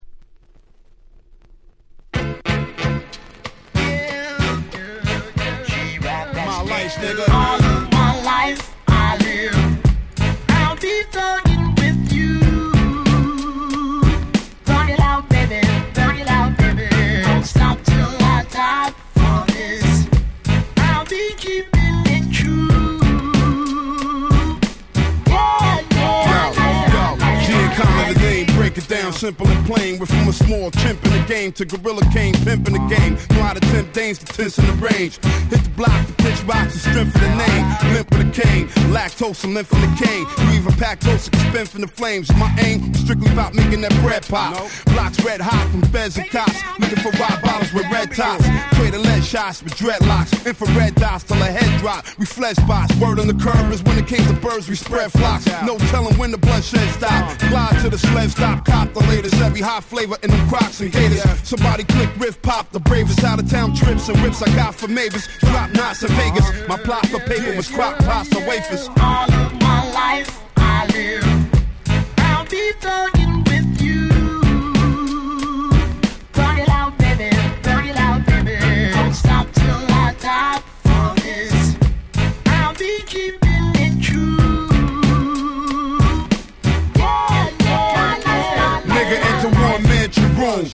01' Smash Hit Hip Hop !!
サビにTalk Boxを使用した未だに超人気の1曲！！
Beatもめちゃくちゃ格好良い！！